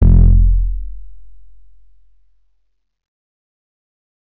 Big Brother (KEY F - 248hz).wav